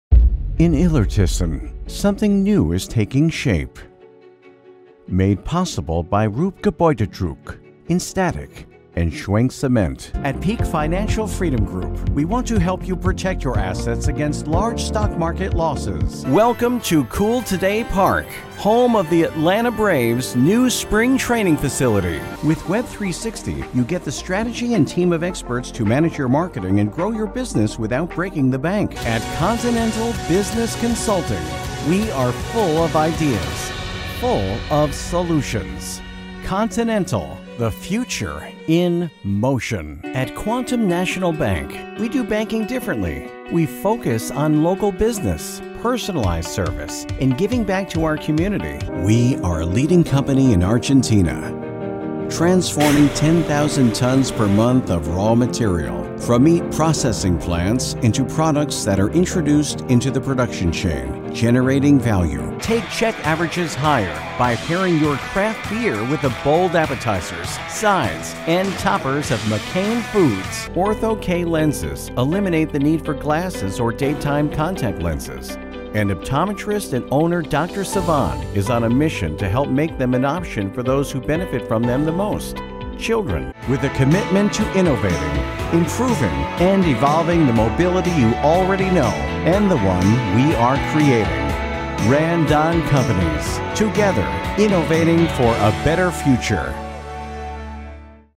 Unternehmensvideos
My style of VO is bright, clear and articulate.
My voice can be described as clear, friendly, personable, confident, articulate, and smooth.
Sennheiser 416 mic